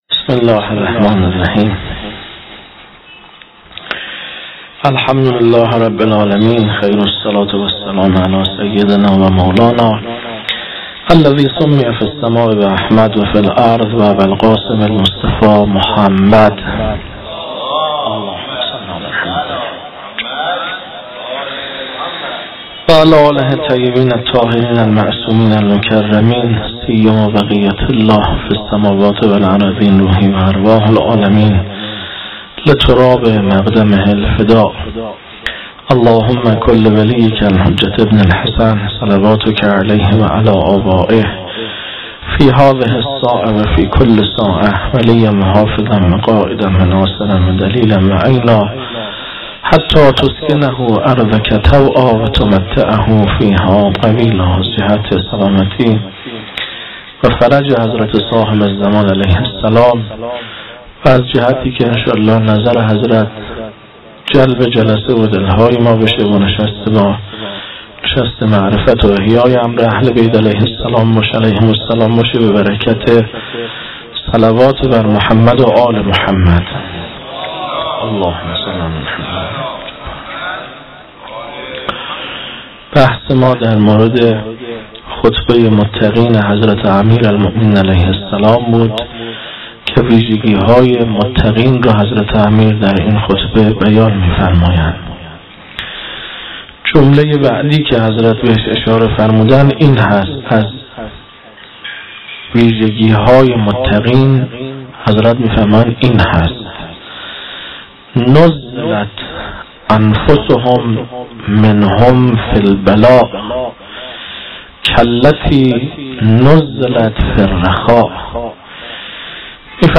سخنرانی ها